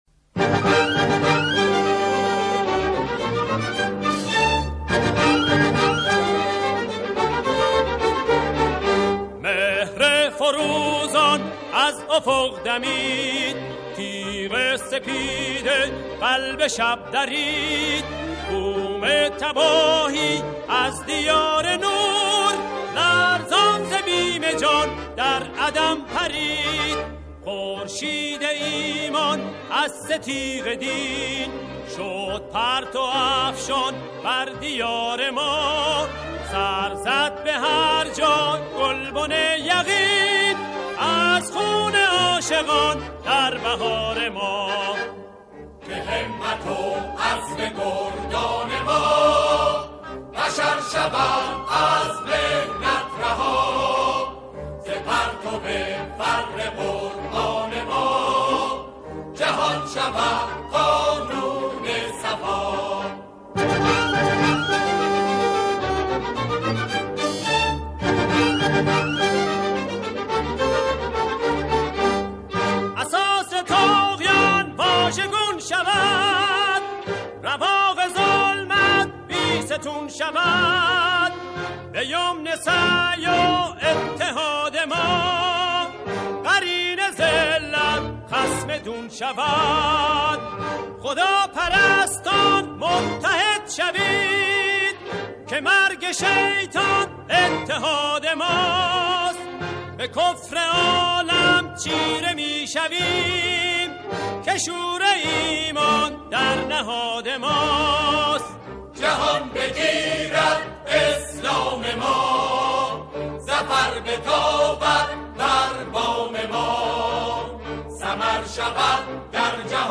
همخوانی